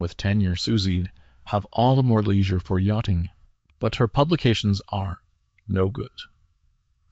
speech-style-transfer text-to-speech voice-cloning